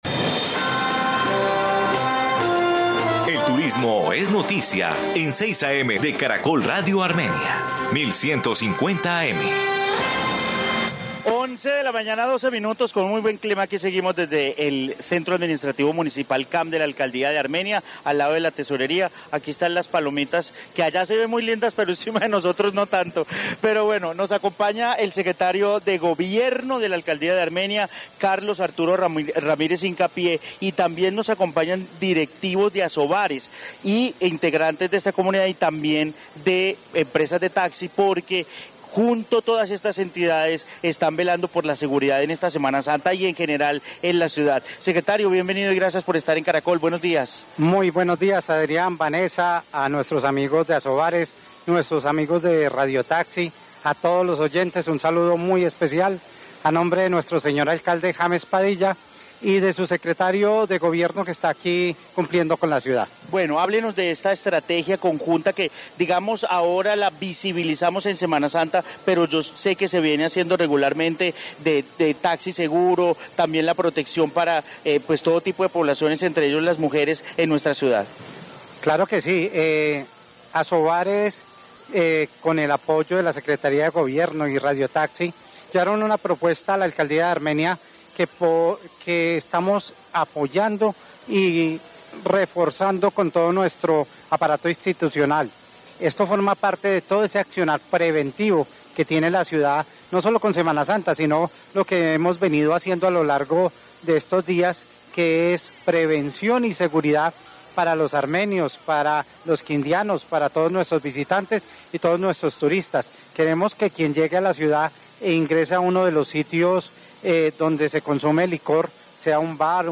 Informe Taxi Seguro en Armenia